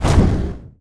Fire Throw.wav